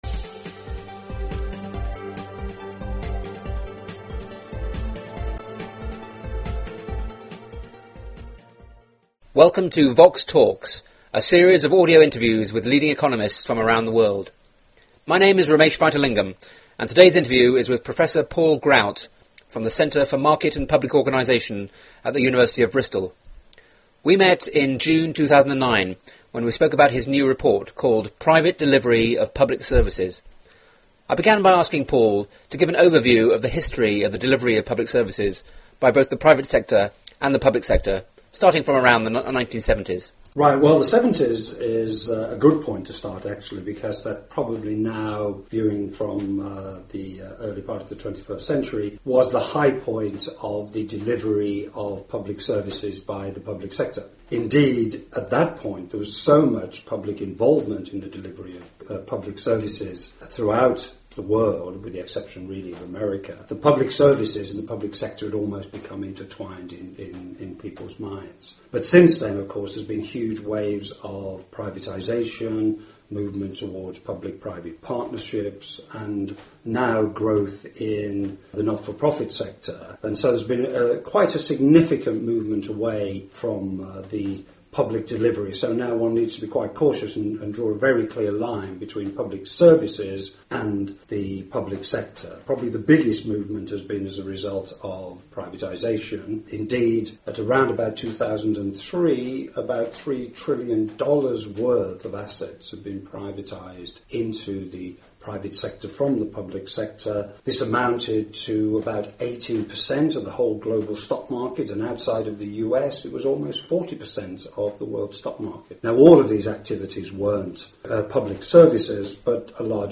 The interview was recorded in Bristol in June 2009